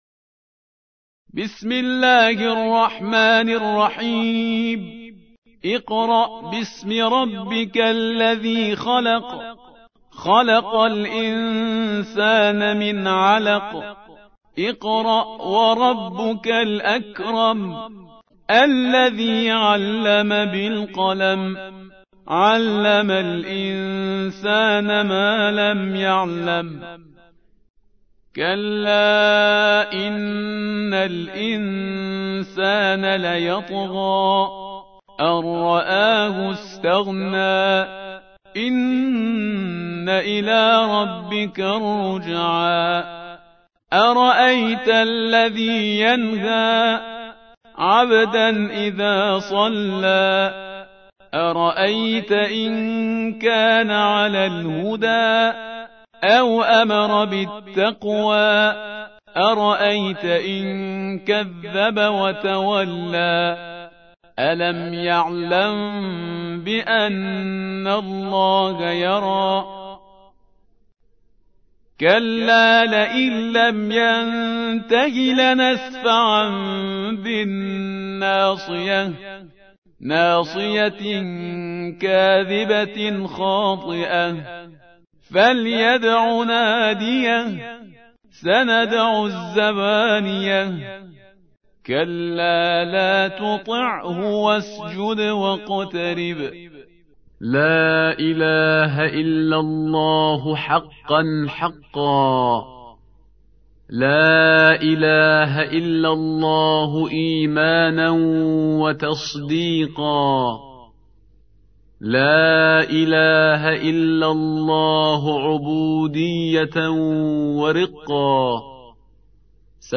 سورة العلق / القارئ